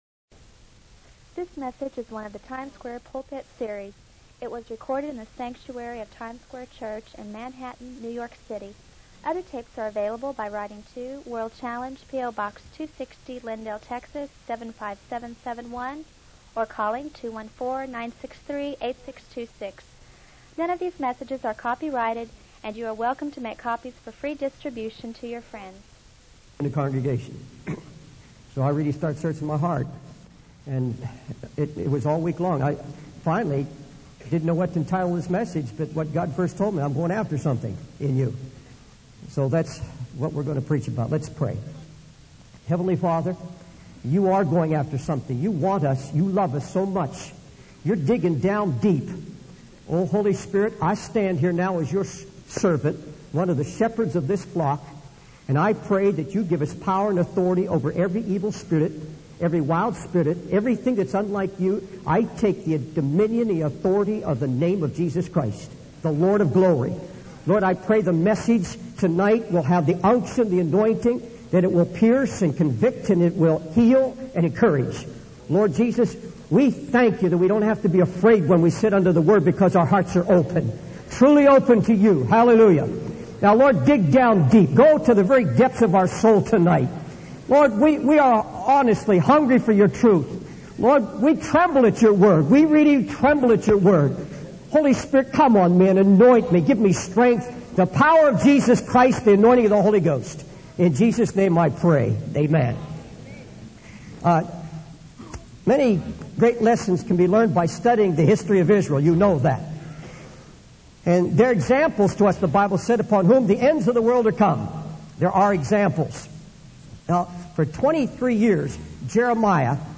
In this sermon, the preacher, inspired by the Spirit of God, reveals the reason for the ruin of the city.
It was recorded in the sanctuary of Times Square Church in Manhattan, New York City.